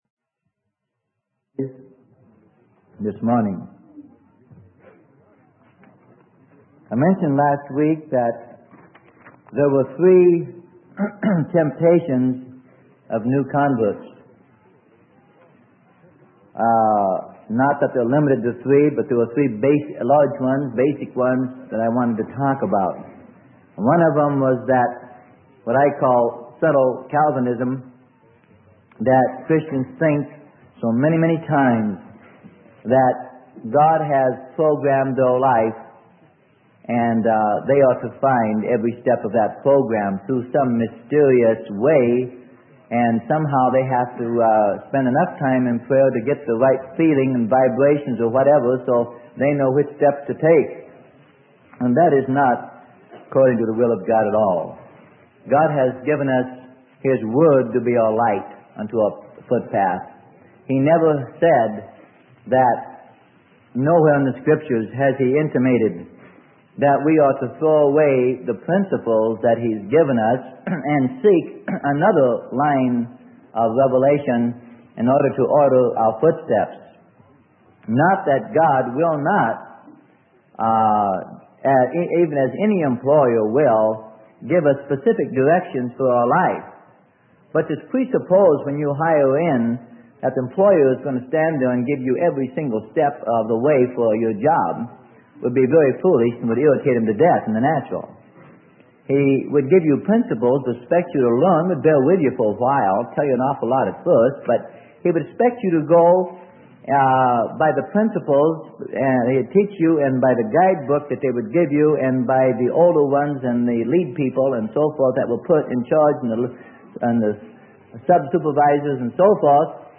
Sermon: How to be Led of God - Part 27 - Direction - Freely Given Online Library